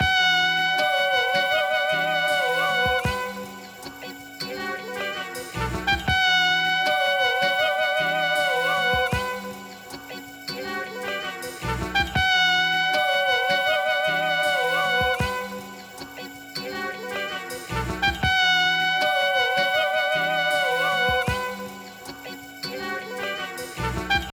Hook Jazz Sample.wav